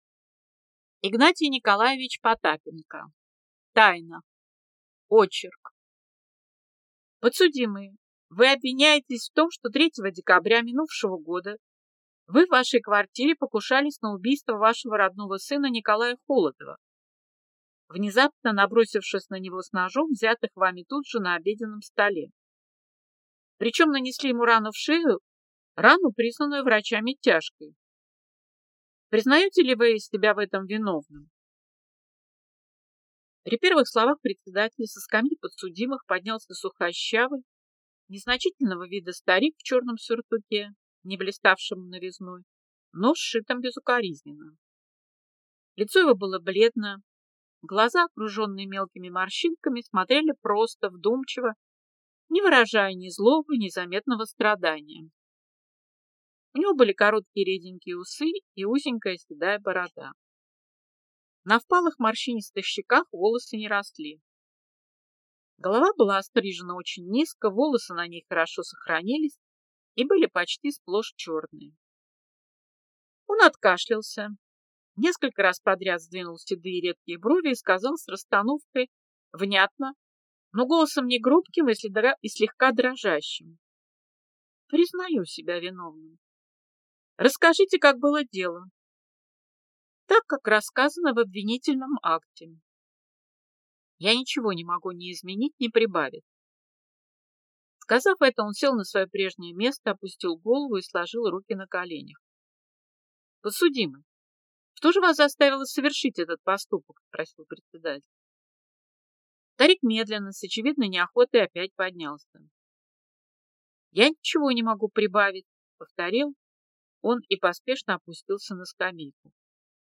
Аудиокнига Тайна | Библиотека аудиокниг